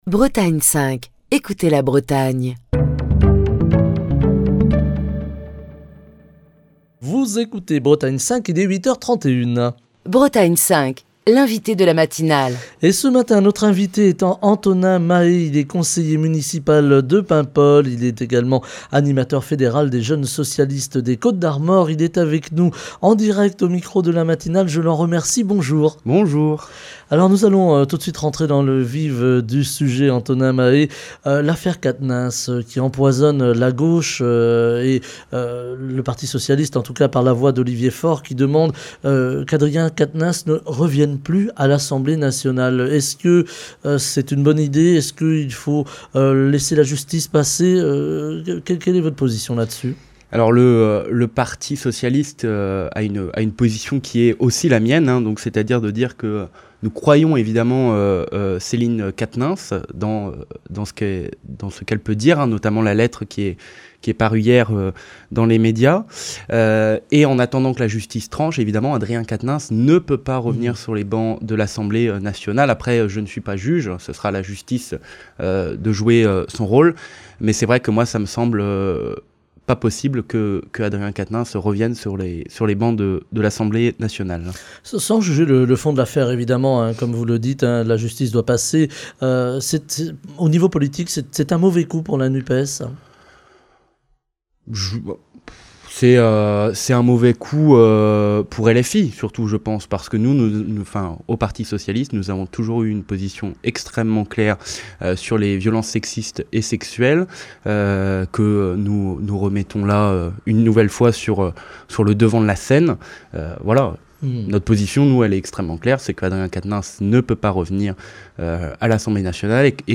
Antonin Mahé, conseiller municipal de Paimpol, animateur fédéral des jeunes socialistes des Côtes-d'Armor | Bretagne5